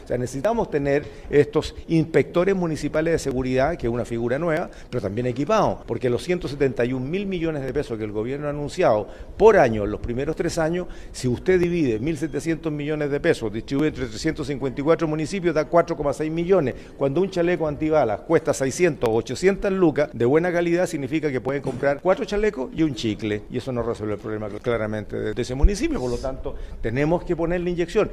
Mientras, el senador Iván Flores (DC), presidente de la Comisión de Seguridad, advirtió que los recursos disponibles son insuficientes para la implementación efectiva del proyecto.